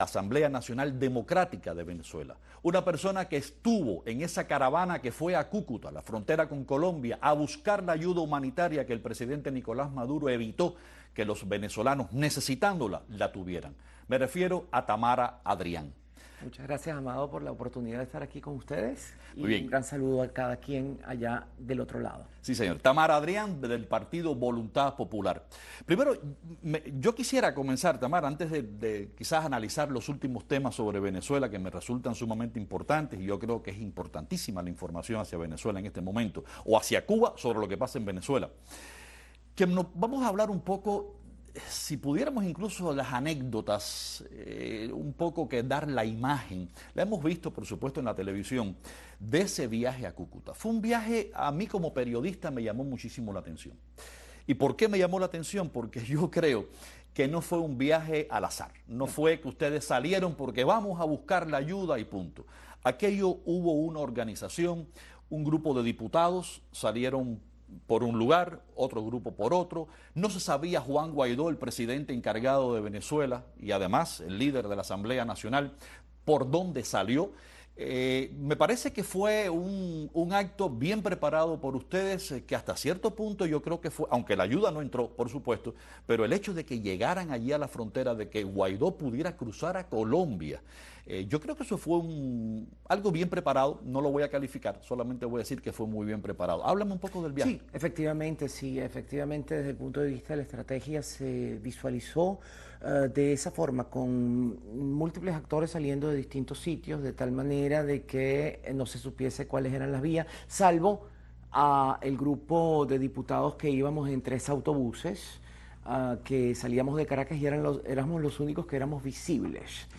En nuestro estudio hoy la diputada de la Asamblea Nacional de Venezuela, Tamara Adrián, del partido Voluntad Popular